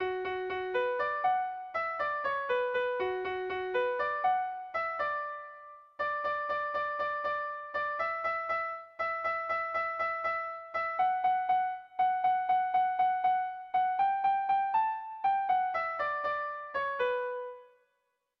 Irrizkoa
Zuberoa < Euskal Herria
Lauko handia (hg) / Bi puntuko handia (ip)
AB